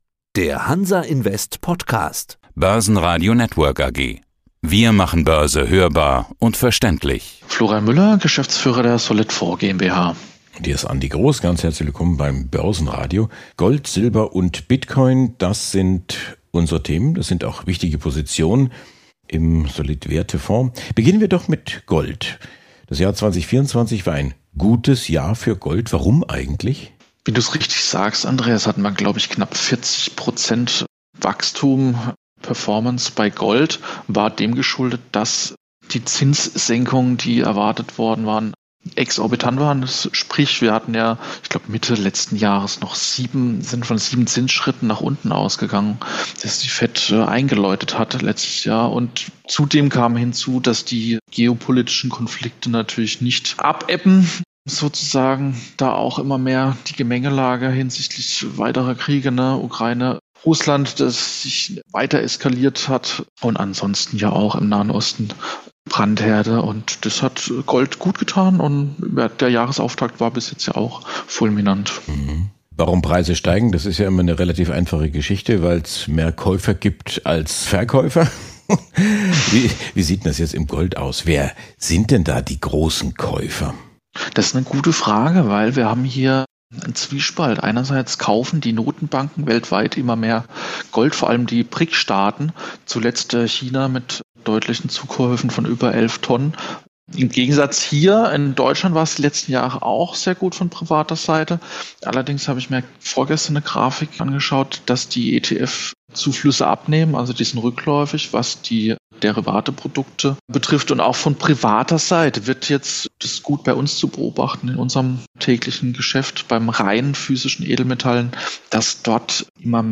Regelmäßig präsentieren sich hier Fondsboutiquen, Portfoliomanager und Initiatoren im Gespräch mit der Börsenradio Redaktion.